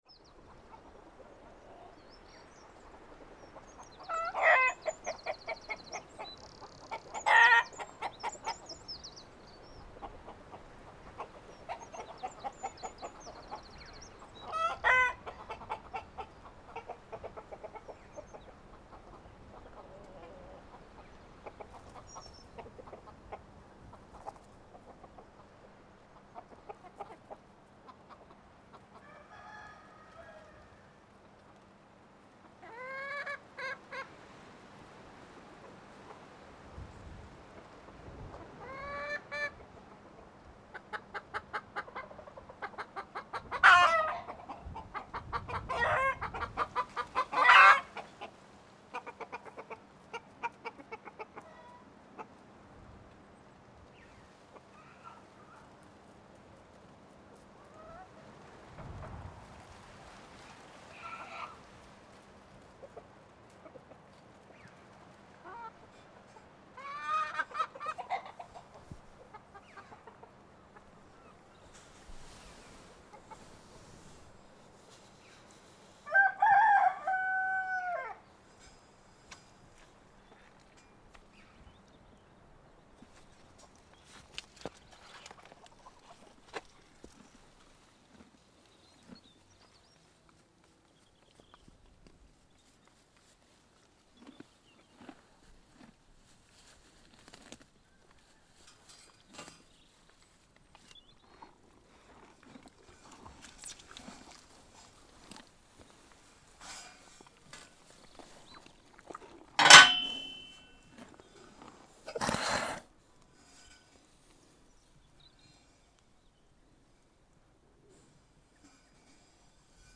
With little interruption from any kind of noise pollution the village and surrounding area features a crystal-clear soundscape of natural found-sounds.
This track features recordings captured during treks through the terrain of Roundstone.
Along my treks I was greeted by donkeys; hens; cows; sheep; birds; bees and horses, all evident in this track. At one point a pair donkeys took great interest in the microphone, sniffing and rubbing against the furry windshield.
‘Cloch Na Rón’ follows and I am softly hit by an aural wave of springtime.